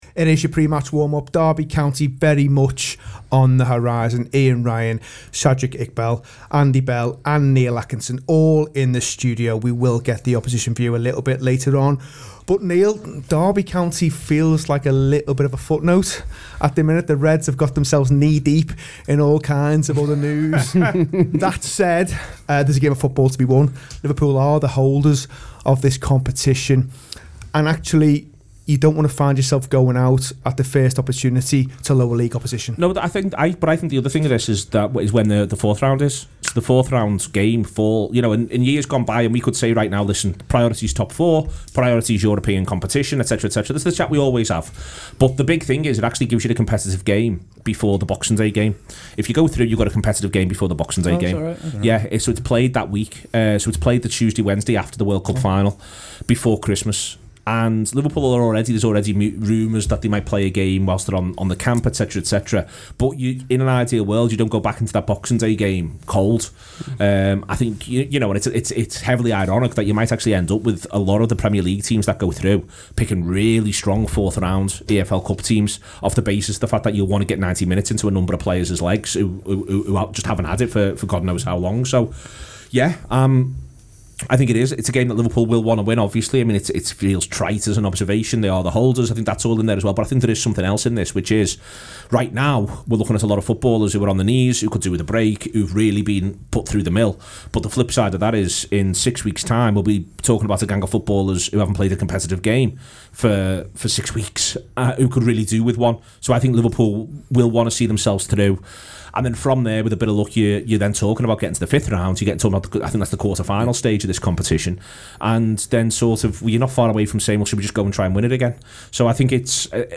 Below is a clip from the show – subscribe for more pre-match on Liverpool v Derby County…